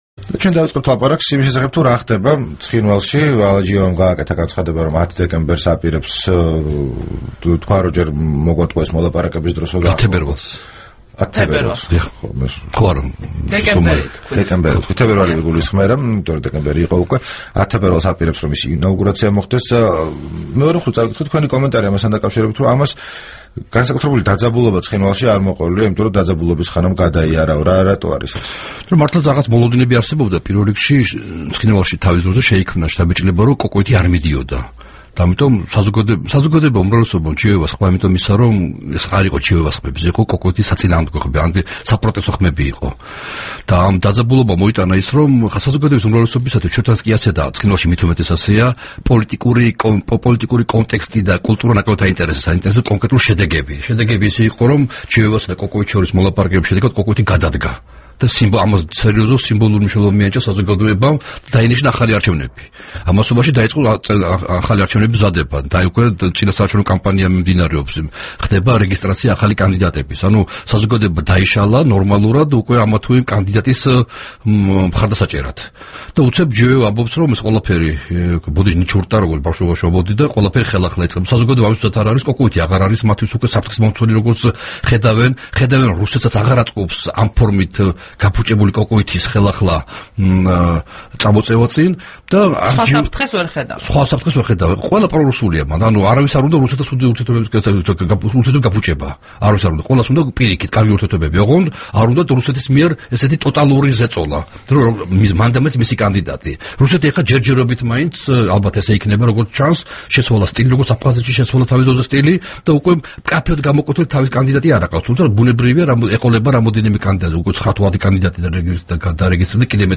რადიო თავისუფლების თბილისის სტუდიაში სტუმრად იყო კონფლიქტოლოგი პაატა ზაქარეიშვილი. მან ცხინვალში შექმნილი პოლიტიკური ვითარება განიხილა და მოვლენების შესაძლო განვითარებაზე ილაპარაკა. საუბარი პაატა ზაქარეიშვილთან